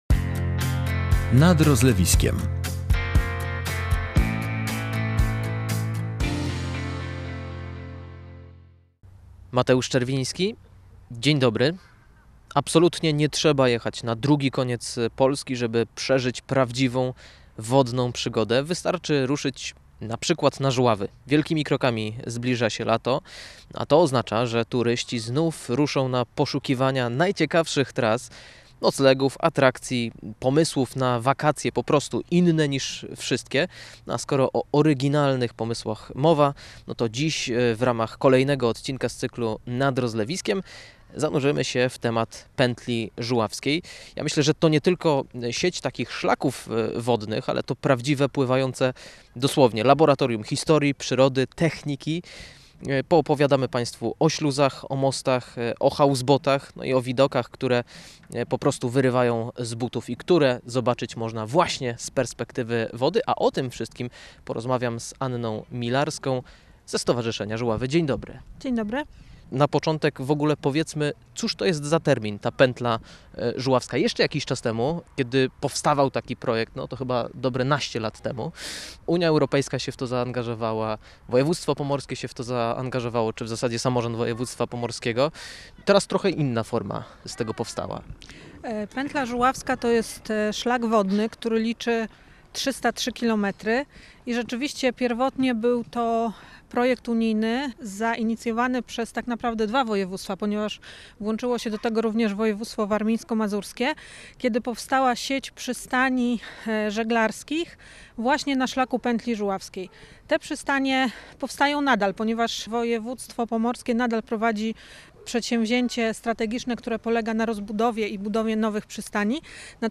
Rozmawiamy o tratwach, houseboatach i miejscach, gdzie mapa wygląda jak labirynt z wodą w tle.